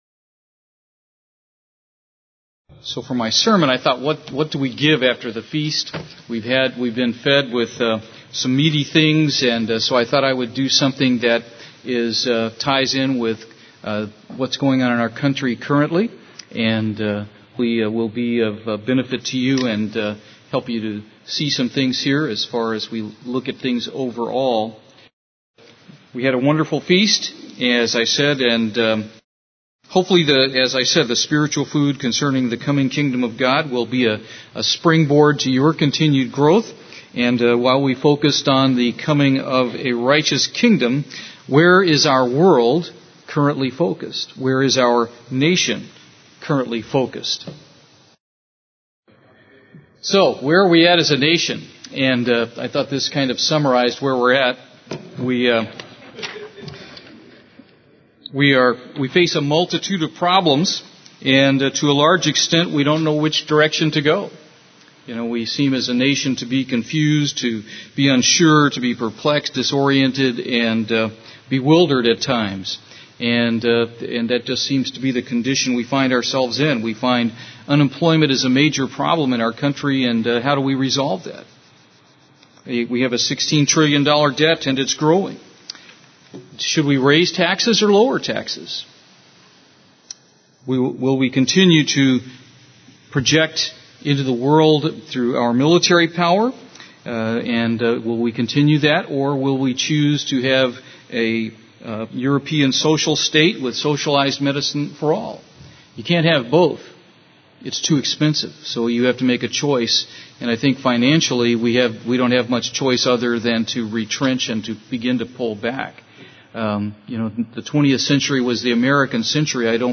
Given in Houston, TX
UCG Sermon Studying the bible?